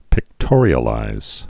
(pĭk-tôrē-ə-līz)